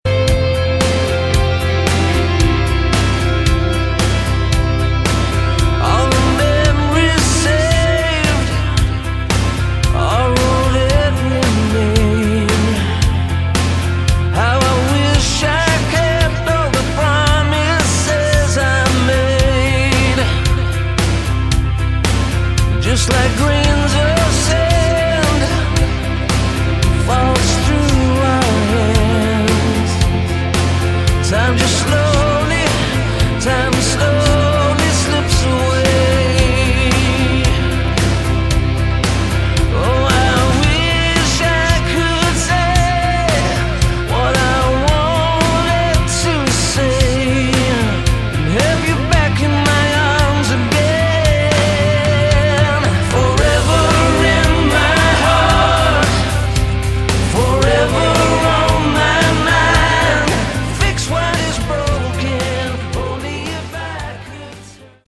Category: Melodic Rock
vocals, guitars, keyboards
lead guitars
bass, backing vocals
drums, percussion
saxophone